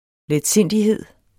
Udtale [ lεdˈsenˀdiˌheðˀ ]